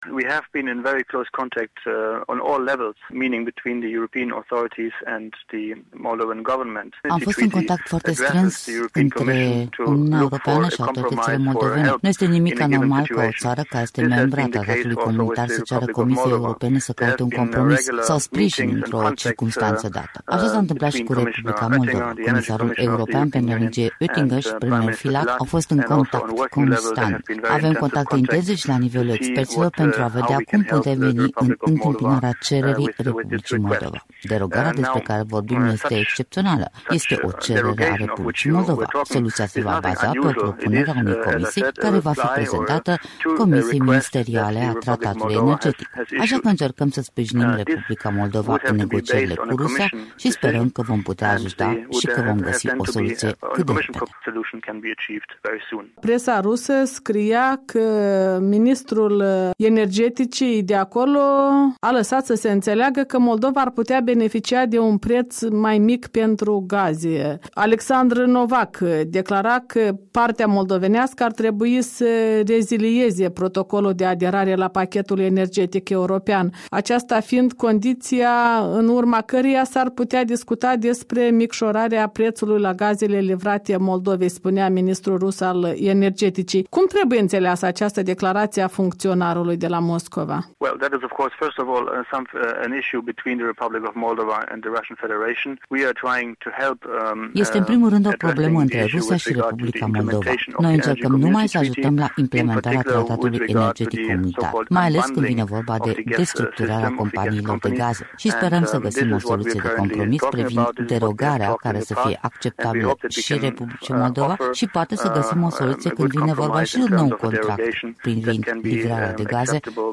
Interviu cu șeful Delegației Europene la Chișinău Dirk Shuebel